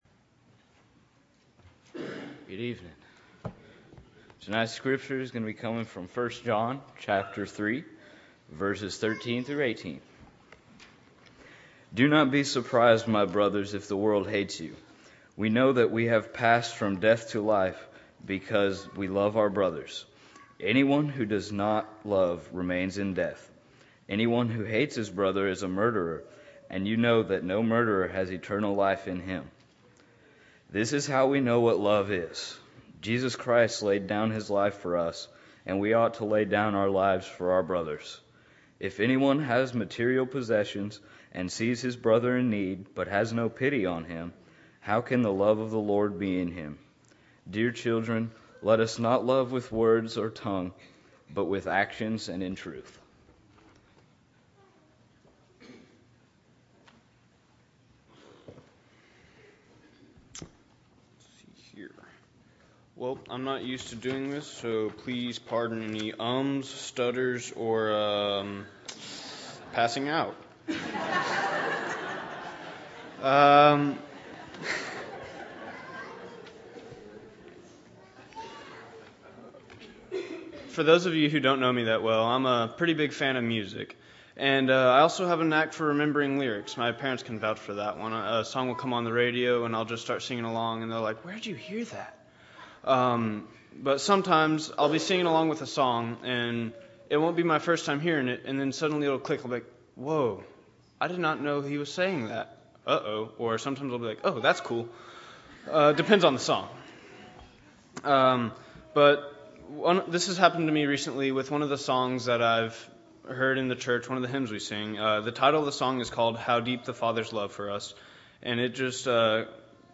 Youth Led Service – Bible Lesson Recording